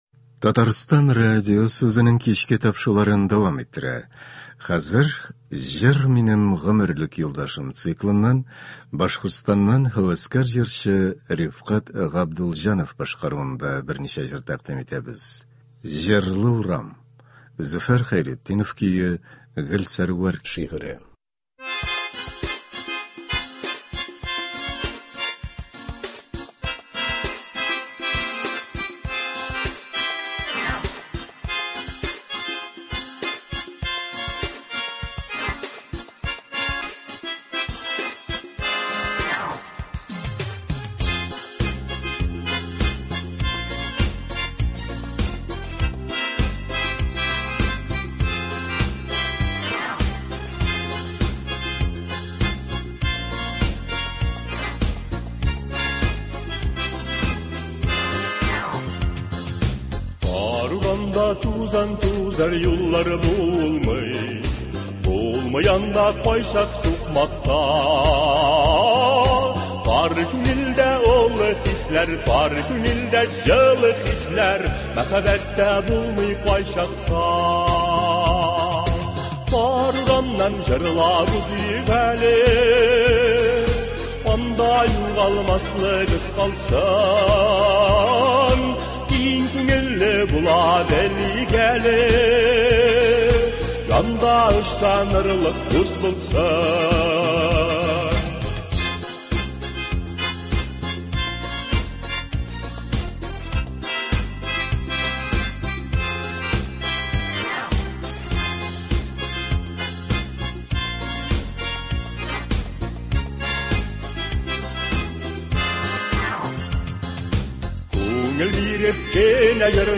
Концерт (20.12.21)